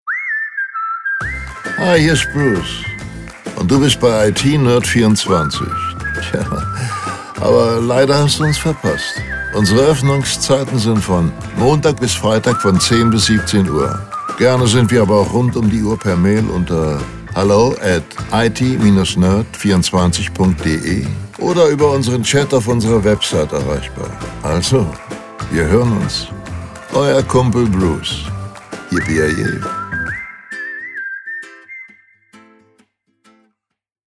AB Ansage mit der deutschen Synchronstimme von Bruce Willis – Manfred Lehmann.